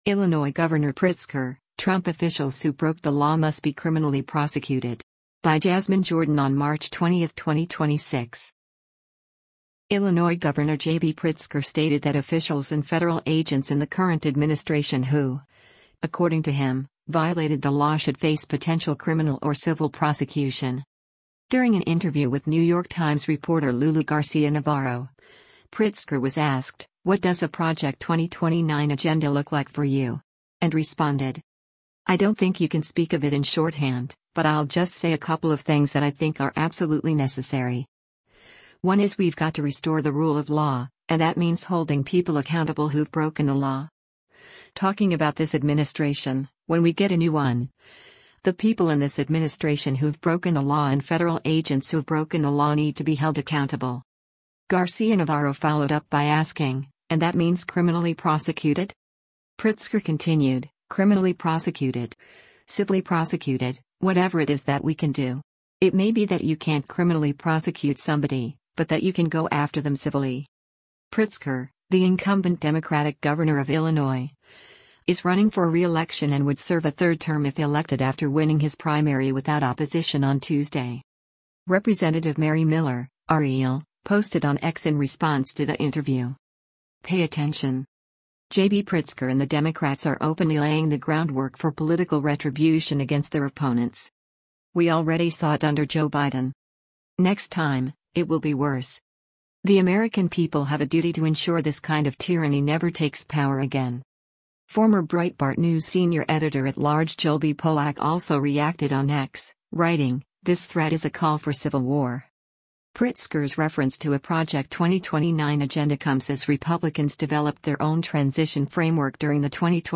During an interview with New York Times reporter Lulu Garcia-Navarro, Pritzker was asked, “What does a project 2029 agenda look like for you?” and responded: